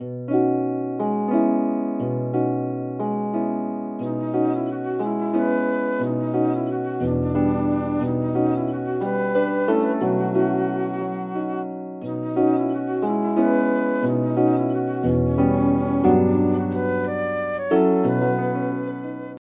• Harp and Flute Version: $21.00
(flute version)